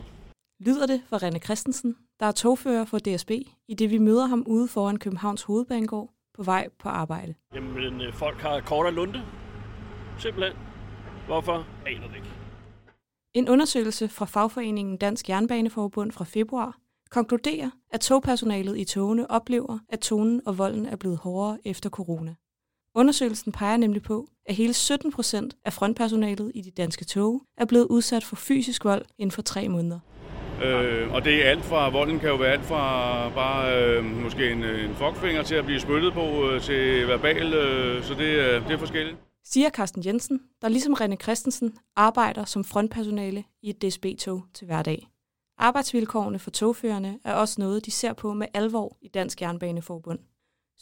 3:30 indslag om vold mod frontpersonale i offentlig transport